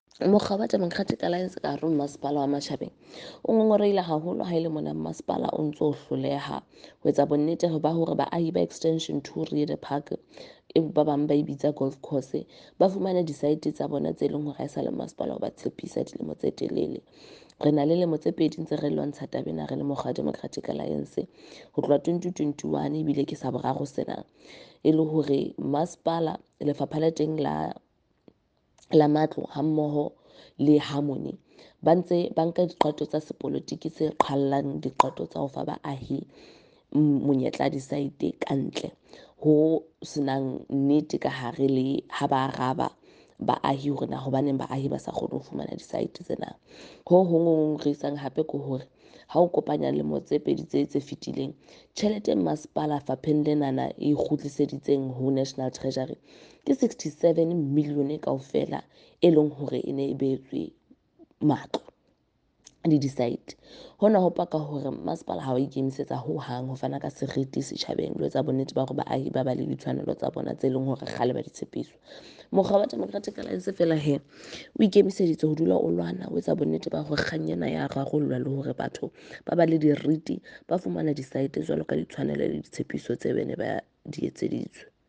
Note to editors: Please find attached English and Afrikaans soundbites by Cllr Igor Scheurkogel and
Sesotho by Karabo Khakhau MP.